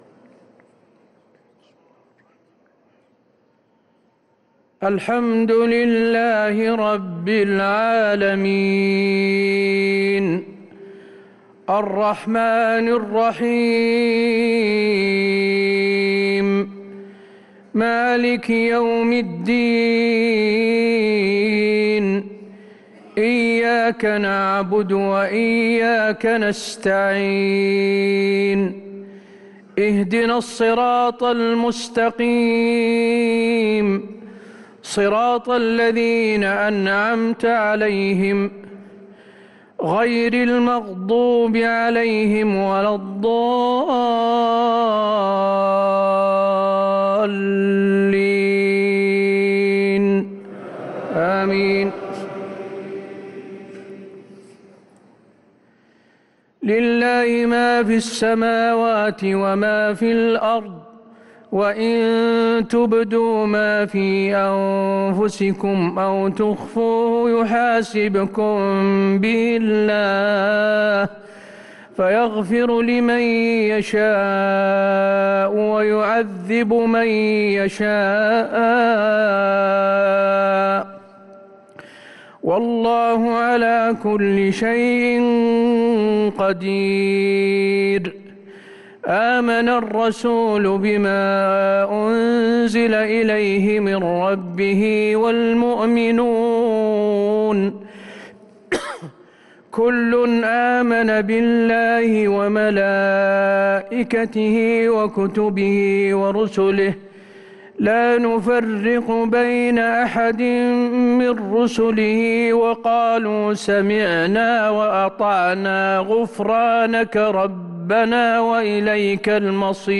صلاة العشاء للقارئ حسين آل الشيخ 1 ذو الحجة 1444 هـ
تِلَاوَات الْحَرَمَيْن .